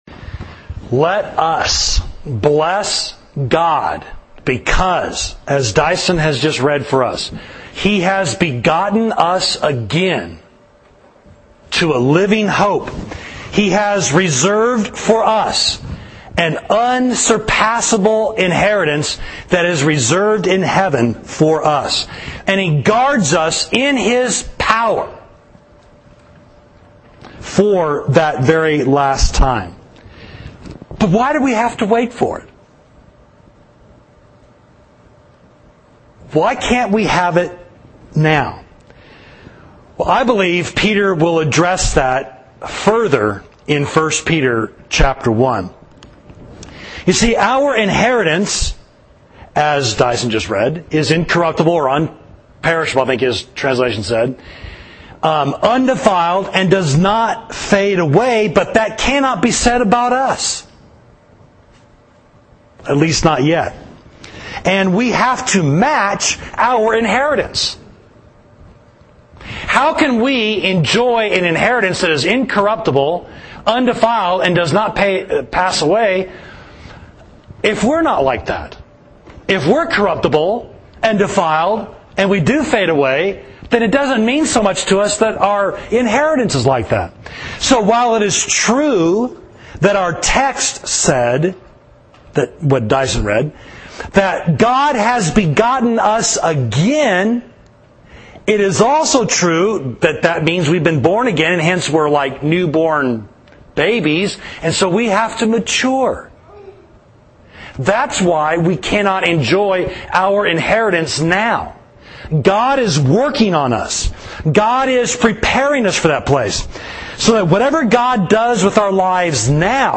Sermon: Why We Have Many Trials, First Peter 1.6–9